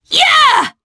Demia-Vox_Attack3_jp.wav